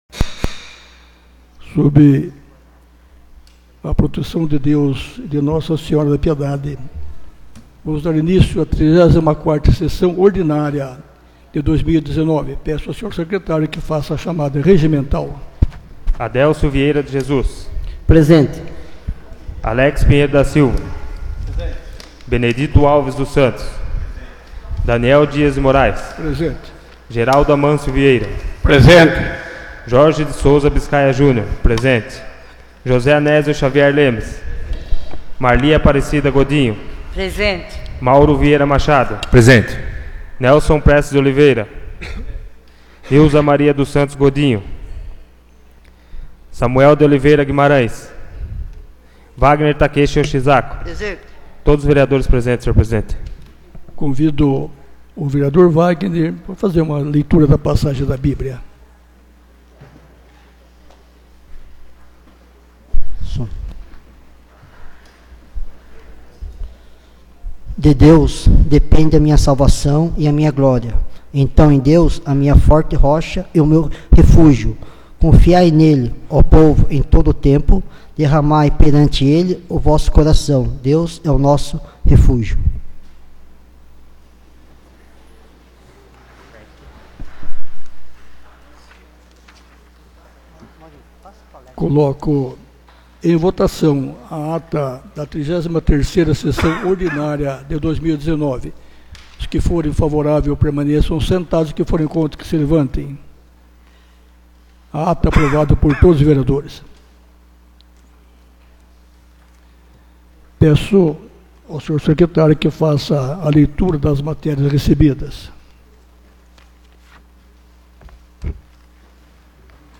34ª Sessão Ordinária de 2019 — Câmara Municipal de Piedade